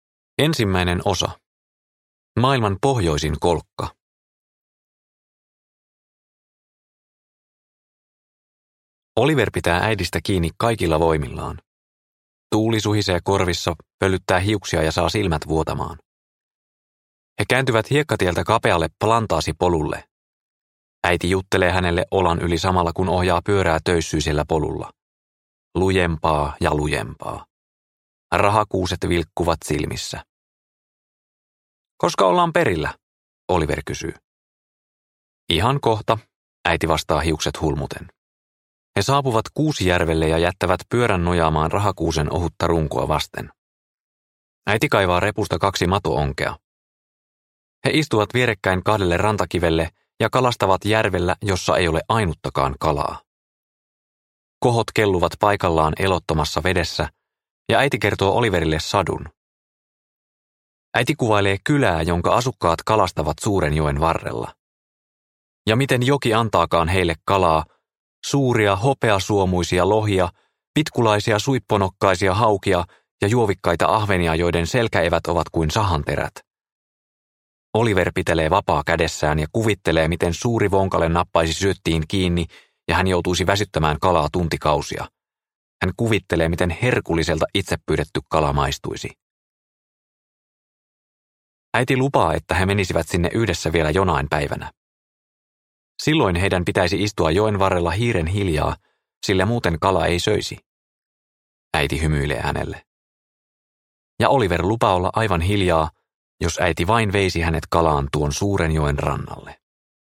Ikimaa - Soturin tie – Ljudbok – Laddas ner